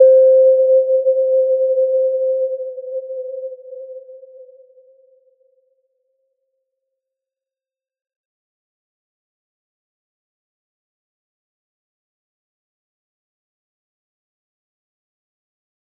Little-Pluck-C5-p.wav